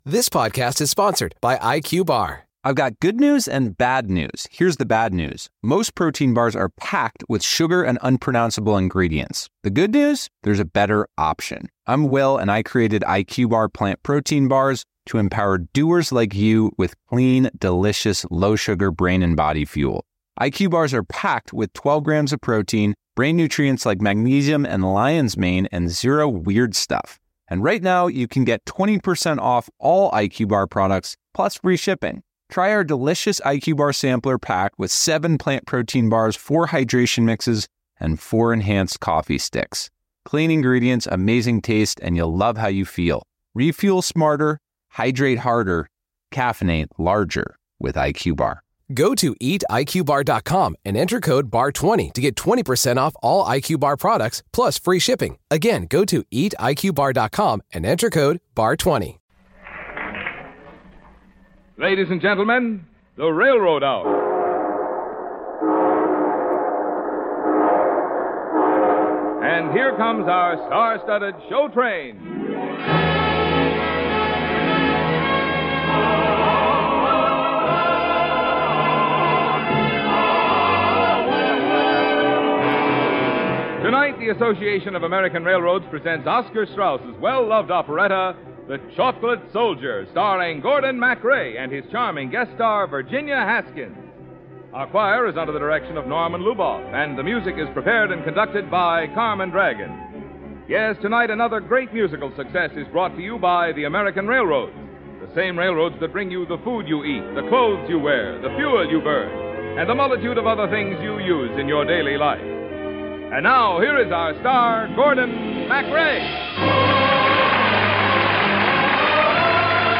radio series that aired musical dramas and comedies